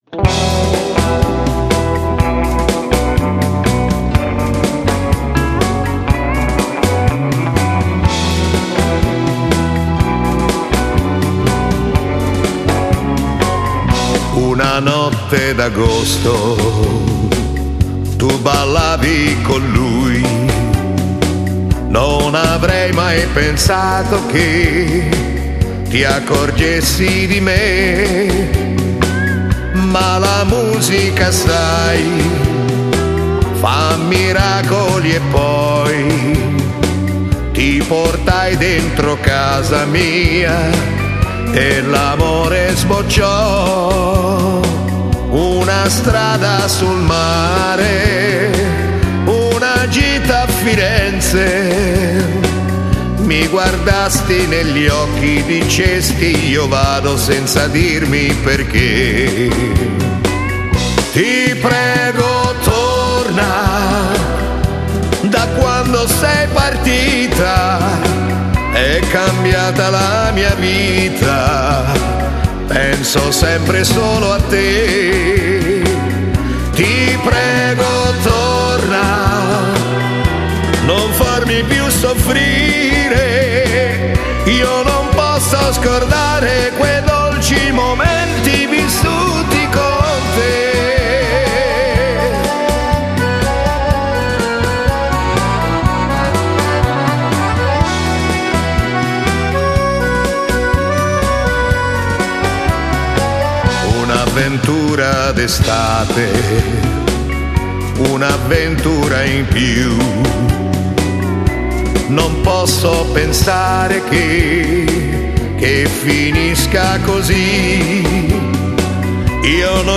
Genere: Beguine